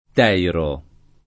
Teiro [ t $ iro ]